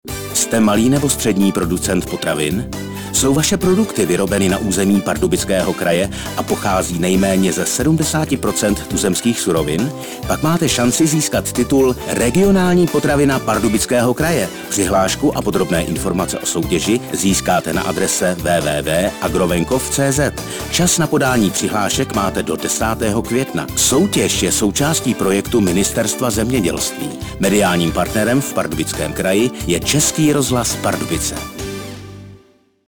Upoutávka na soutěž Regionální potravina pardubického kraje 2012.